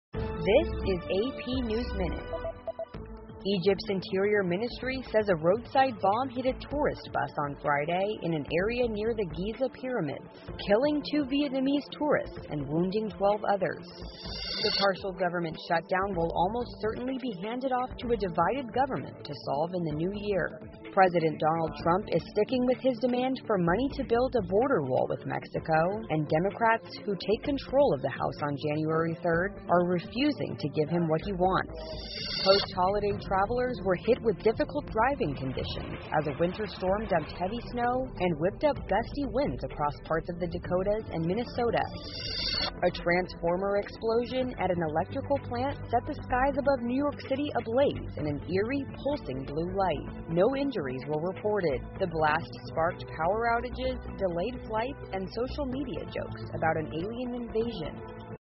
美联社新闻一分钟 AP 纽约市发电厂爆炸 听力文件下载—在线英语听力室